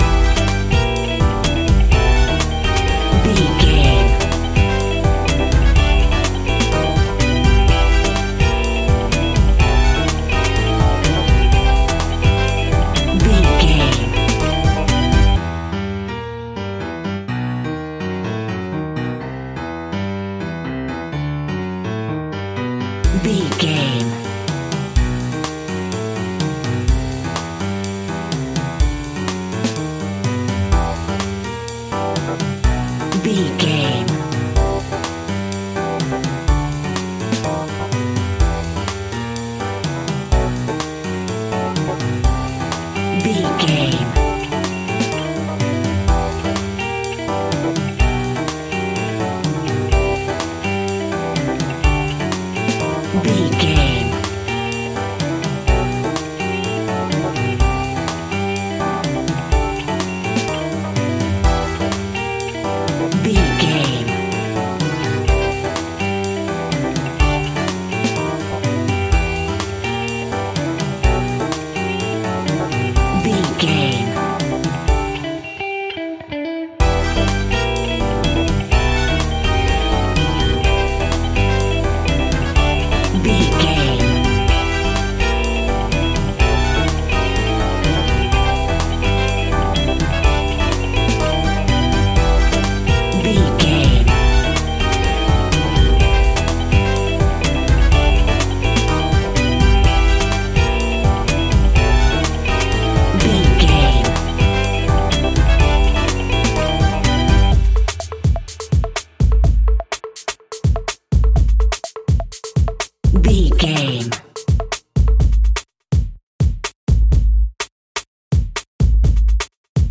Exotic, spicy and from another world!
Diminished
Funk
salsa
funky instrumentals
energetic
electric guitar
bass guitar
drums
hammond organ
fender rhodes
percussion